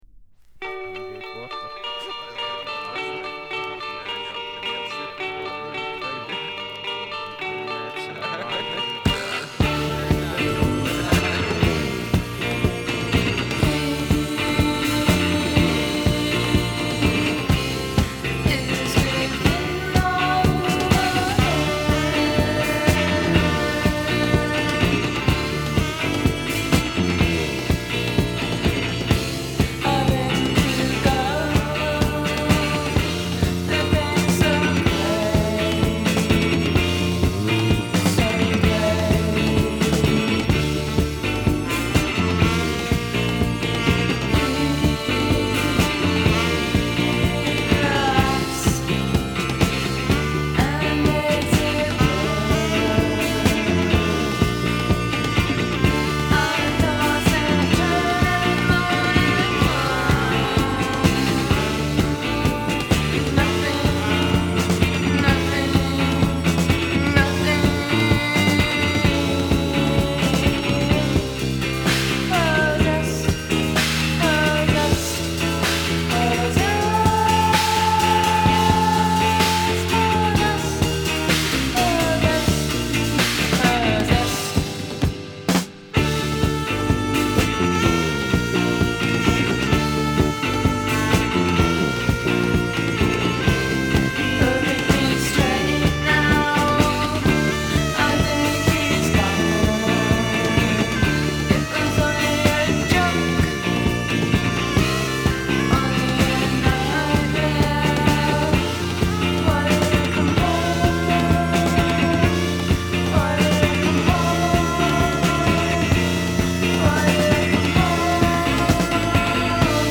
息が詰まりそうな陰鬱かつトライバルなポストパンクを叩きつける。呪術的な女性ヴォーカルが印象的だ。